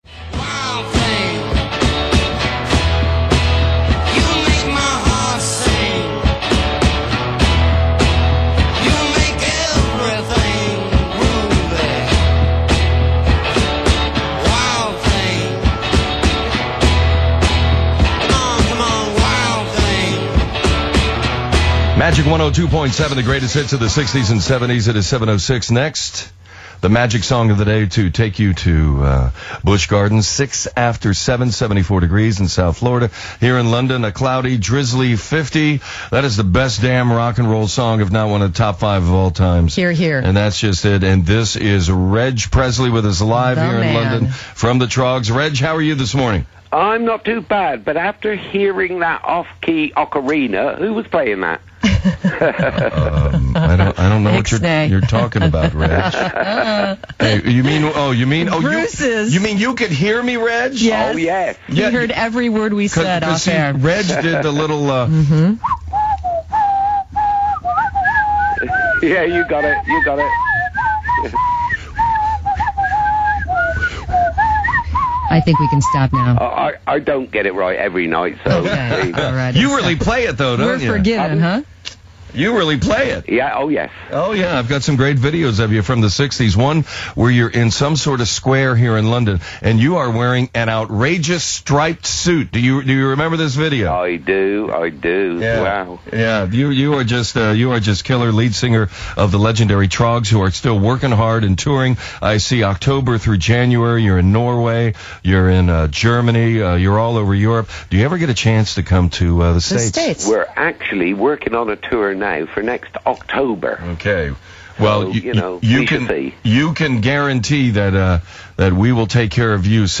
a link to an interview that he set up with Reg at Magic 102.7, recorded 25 Oct 2007.